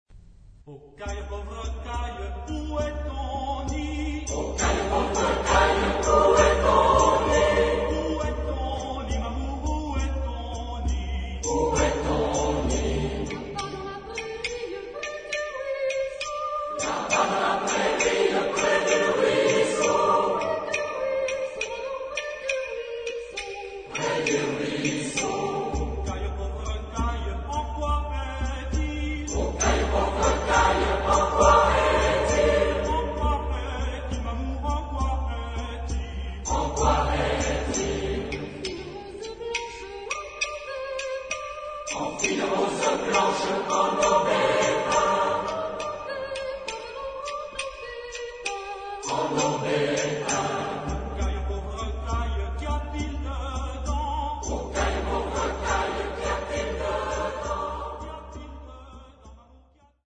Genre-Style-Form: Secular ; Popular ; Bourrée
Mood of the piece: dancing
Type of Choir: SATB  (4 mixed voices )
Soloist(s): Soprano (1) / Ténor (1)  (2 soloist(s))
Tonality: G minor
Origin: Limousin (F)